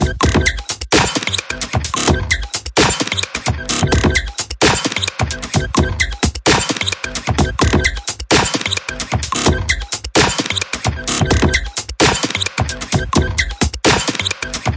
他们的女人" 鼓01
Tag: 寒意 旅行 电子 舞蹈 looppack 样品 毛刺 节奏 节拍 低音 实验 器乐